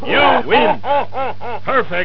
You-win-perfect+laugh.wav